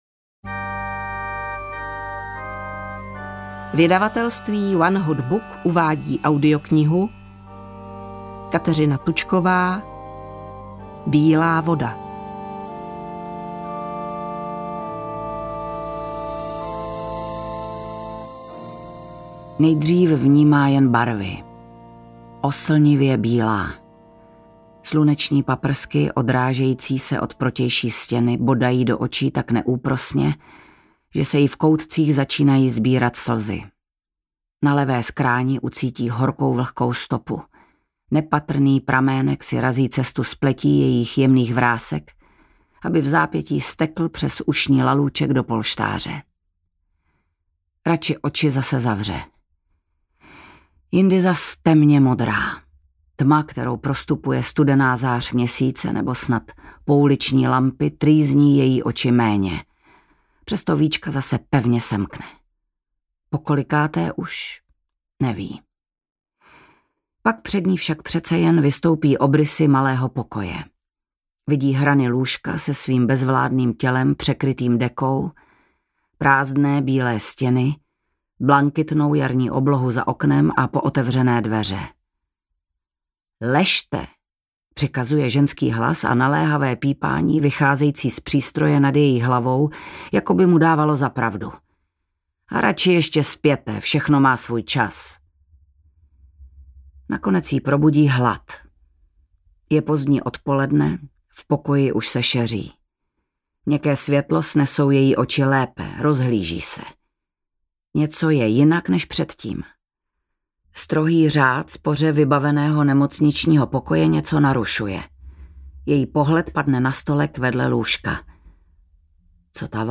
Bílá Voda audiokniha
Ukázka z knihy